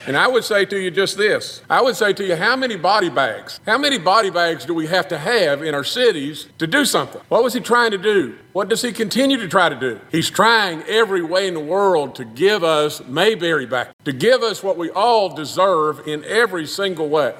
Senators Shelly Moore Capito and Jim Justice of West Virginia have both taken to the Senate floor in Washington to pay tribute to two National Guard members from their state that were shot while on duty there, with one dying from their injuries. Senator Justice said he knows people disagree about President Trump’s use of the Guard, but believes it will help the country return to a more peaceful time…